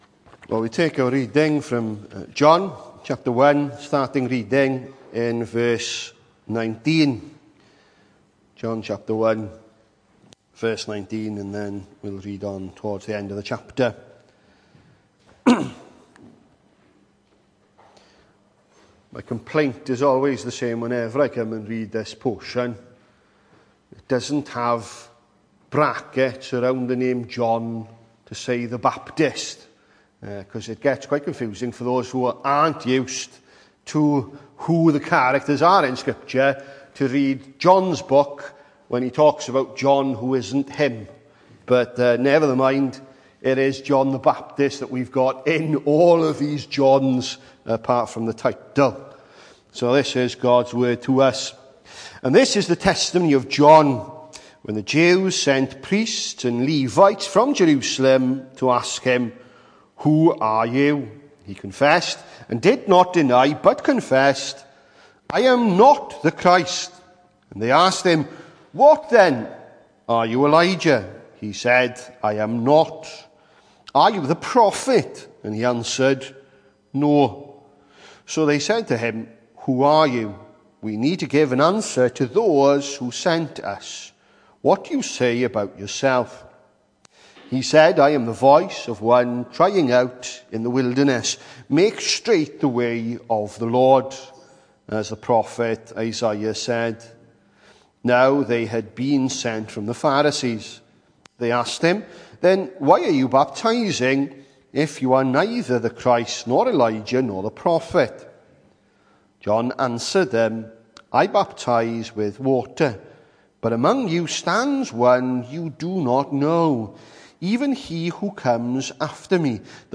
Hello and welcome to Bethel Evangelical Church in Gorseinon and thank you for checking out this weeks sermon recordings.
The 3rd of November saw us hold our evening service from the building, with a livestream available via Facebook.